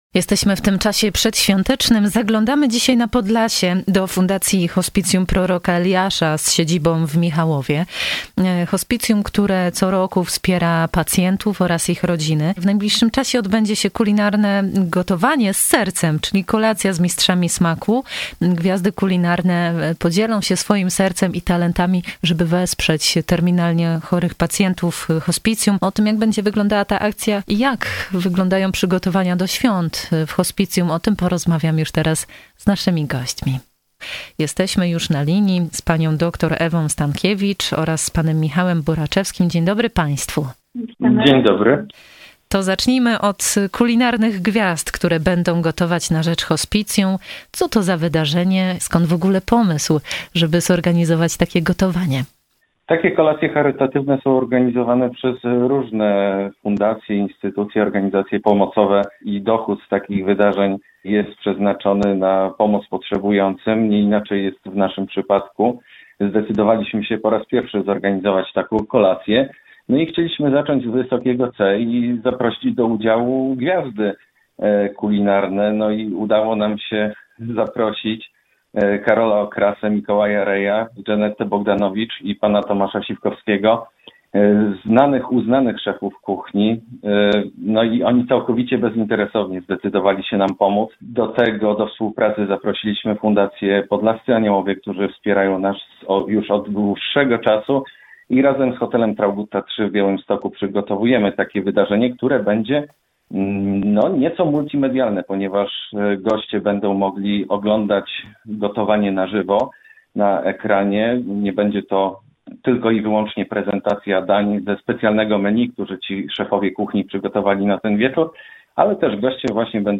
Więcej w rozmowie z naszymi gośćmi
01_Hospicjum-Proroka-Eliasza_rozmowa.mp3